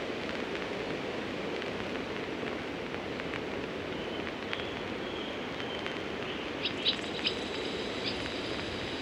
LayeredNoiseAtmos.wav